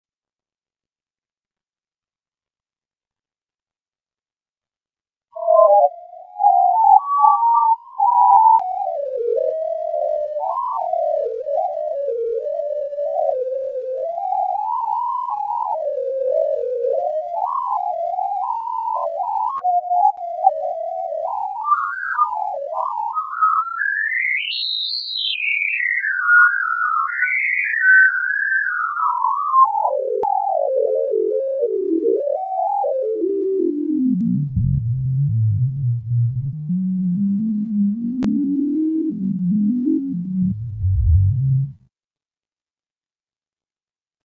Or, try the low-pitch version, 1ST GRAFF Transformed